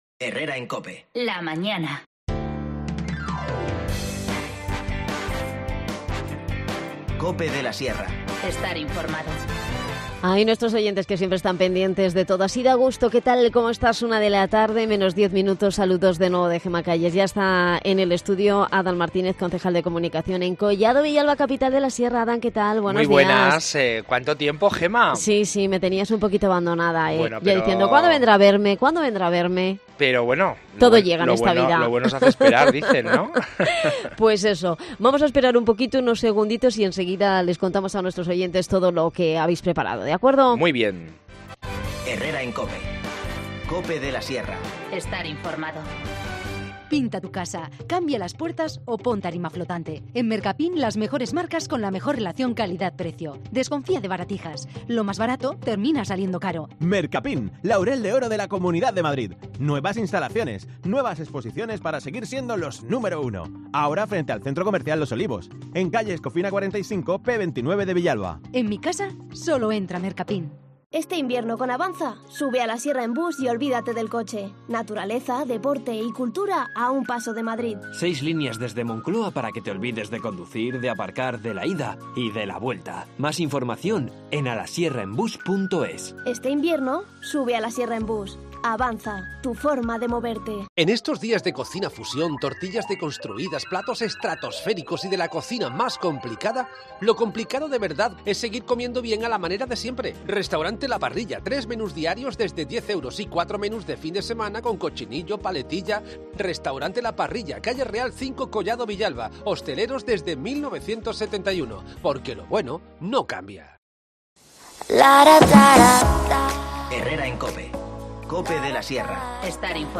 Redacción digital Madrid - Publicado el 06 feb 2020, 13:10 - Actualizado 16 mar 2023, 17:33 1 min lectura Descargar Facebook Twitter Whatsapp Telegram Enviar por email Copiar enlace Nos visita Adan Martínez, concejal de Comunicación de Collado Villlalba, para hablarrnos de los presupuestos de 2020, los nuevos cursos de formación on line, los carnavales y las citas culturales para los próximos días